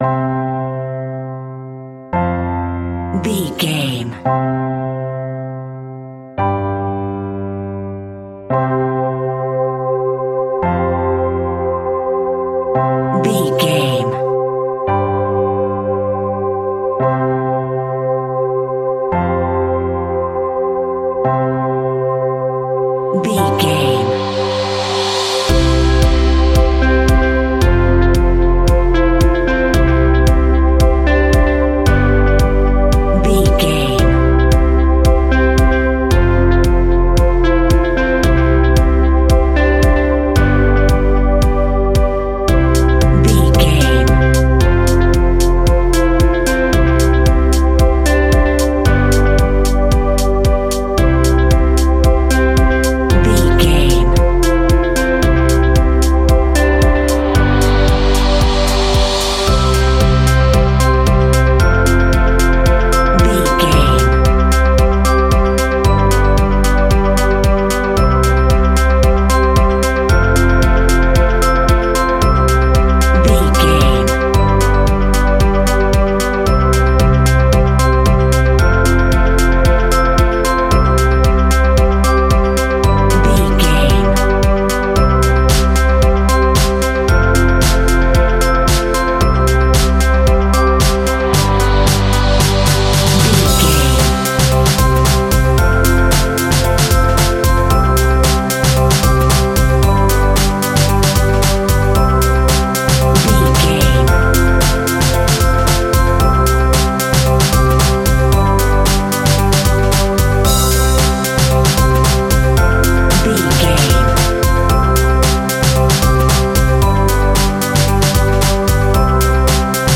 Ionian/Major
energetic
uplifting
catchy
upbeat
acoustic guitar
electric guitar
drums
piano
organ
bass guitar